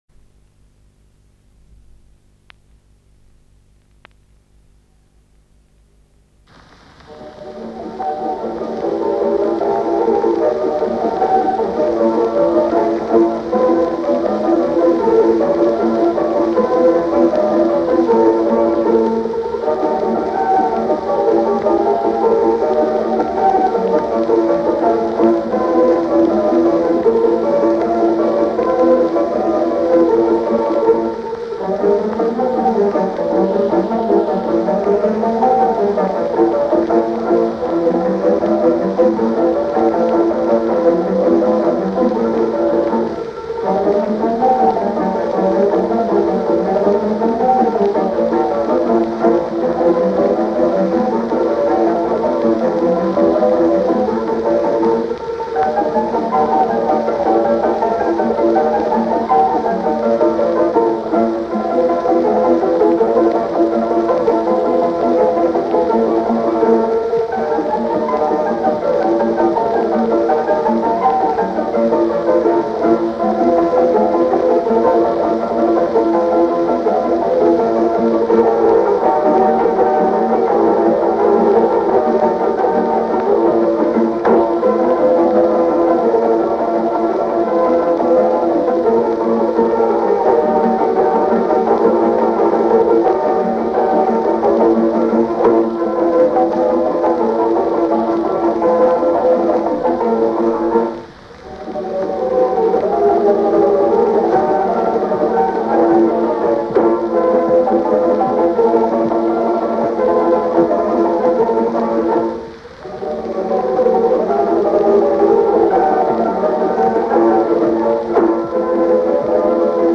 "Светит месяц" (исполн. Великорусский оркестр В.Андреева)